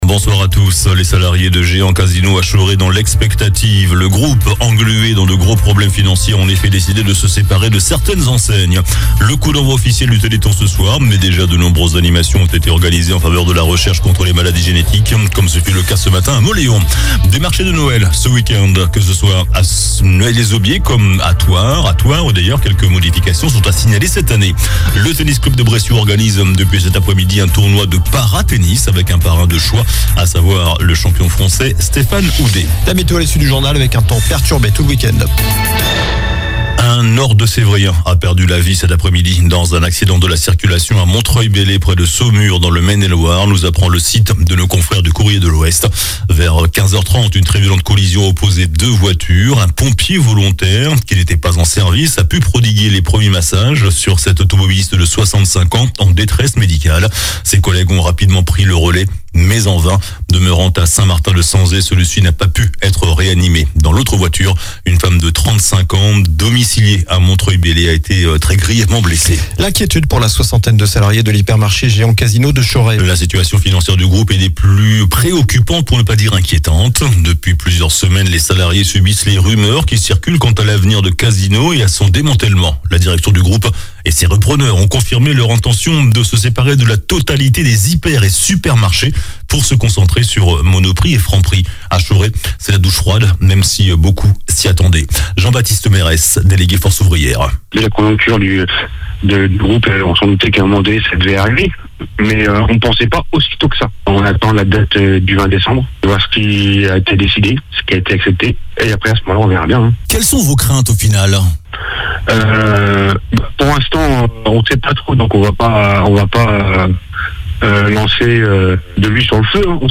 JOURNAL DU VENDREDI 08 DECEMBRE ( SOIR )